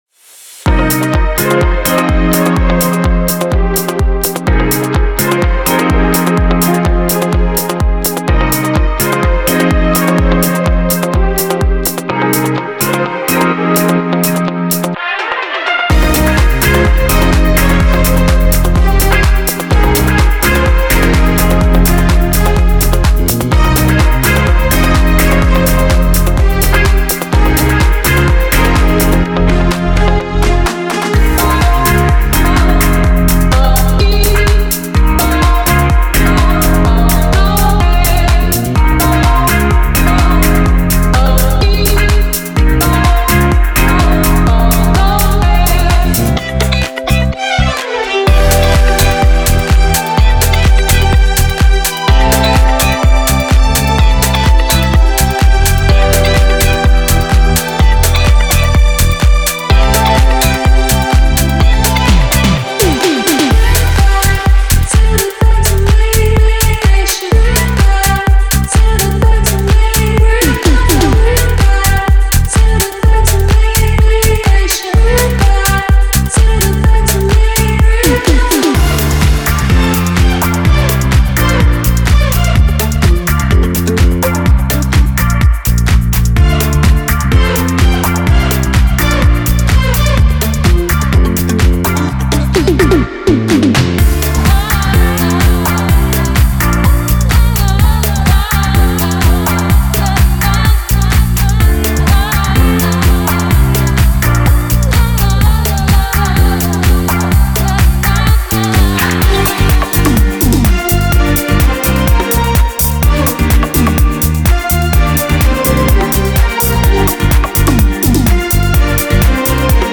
Genre:Disco
829MBの豊かな24ビットサンプルで構成され、テンポは126BPMに固定されています。
デモサウンドはコチラ↓
126 BPM
113 Wav Loops (Bass Guitars, Guitars, Drums, Vox, Fx)